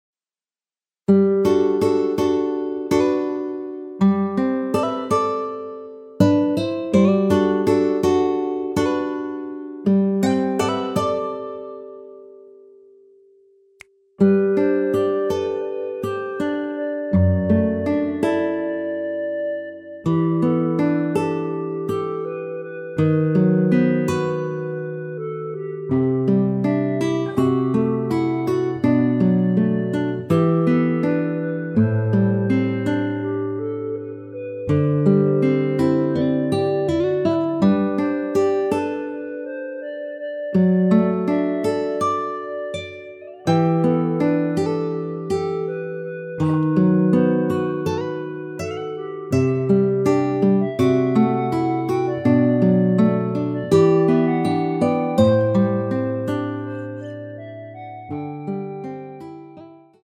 원키에서(+2)올린 멜로디 포함된 MR입니다.
앞부분30초, 뒷부분30초씩 편집해서 올려 드리고 있습니다.